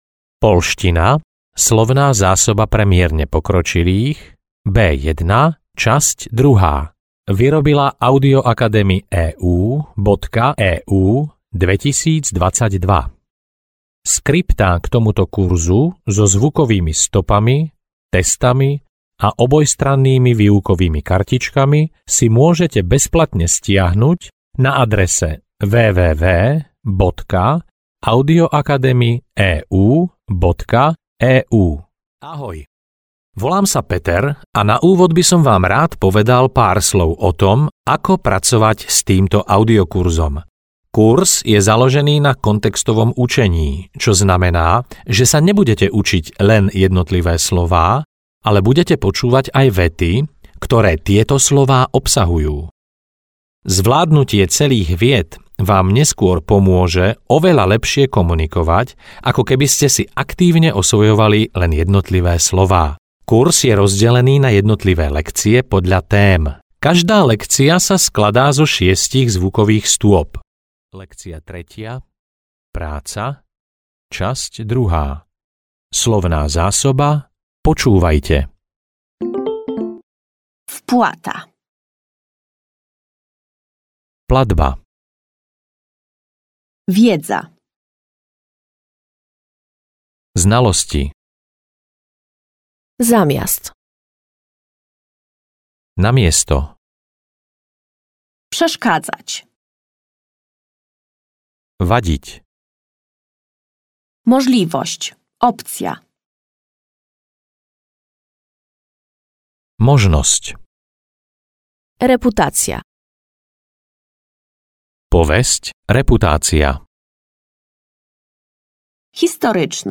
Poľština pre mierne pokročilých B1 – časť 2 audiokniha
Ukázka z knihy